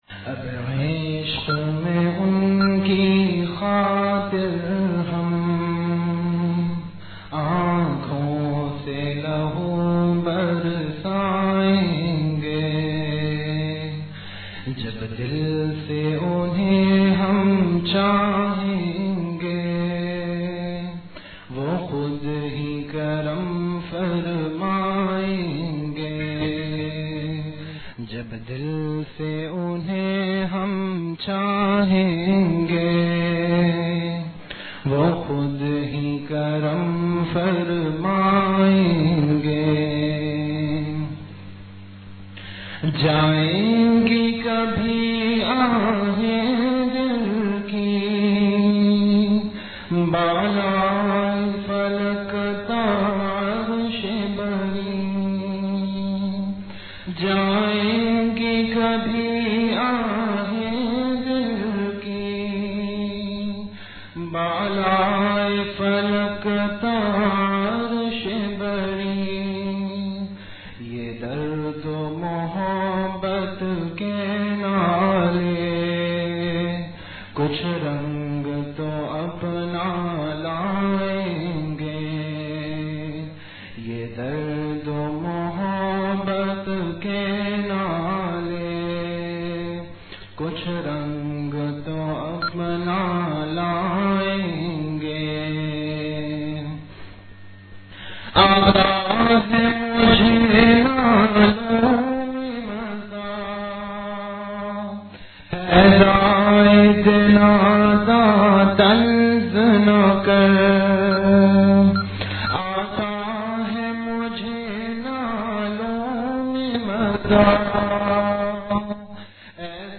Delivered at Home.
Majlis-e-Zikr
After Isha Prayer